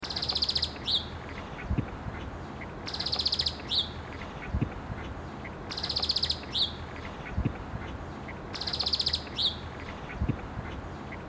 Aphrastura spinicauda
Nome em Inglês: Thorn-tailed Rayadito
Localidade ou área protegida: Malargüe
Condição: Selvagem
Certeza: Fotografado, Gravado Vocal
rayadito.mp3